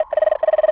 cartoon_electronic_computer_code_08.wav